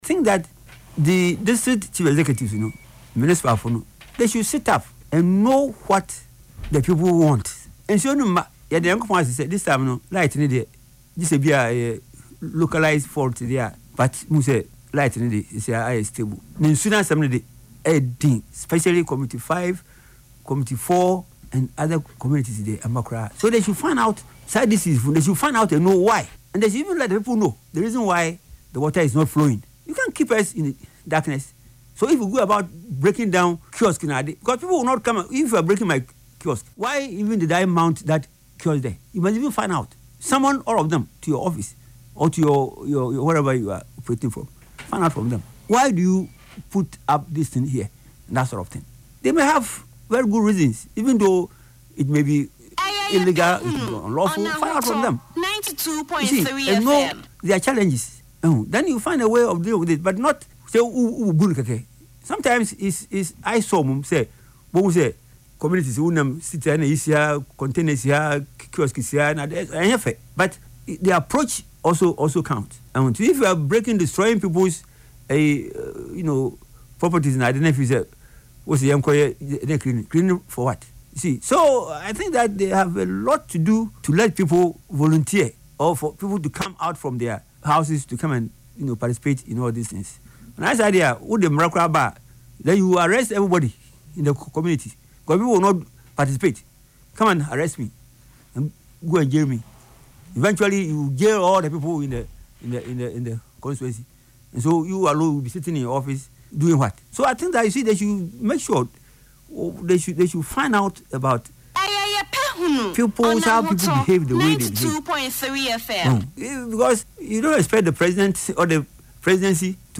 Speaking on Ahotor FM’s “Yepe Ahunu” show on Saturday, November 1